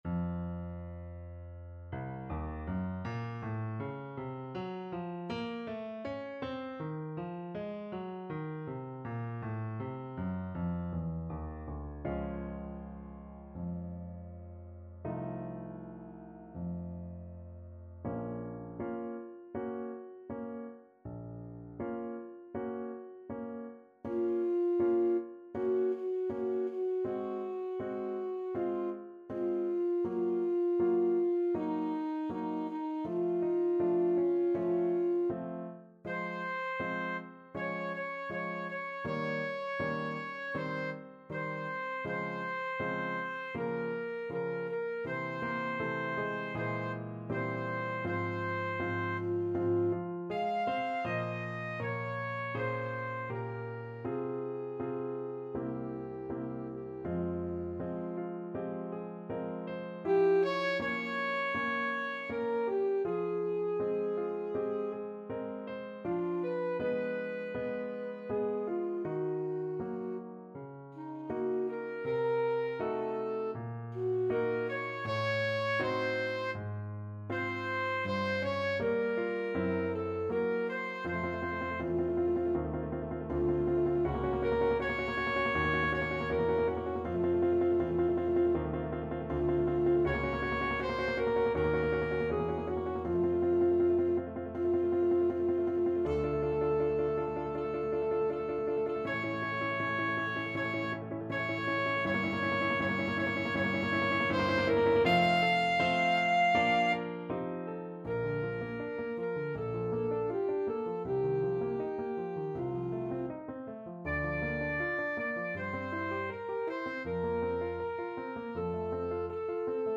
(Repentir) Alto Saxophone version
Alto Saxophone
Bb minor (Sounding Pitch) G minor (Alto Saxophone in Eb) (View more Bb minor Music for Saxophone )
4/4 (View more 4/4 Music)
~ = 100 Molto moderato =80
Bb4-G6
Classical (View more Classical Saxophone Music)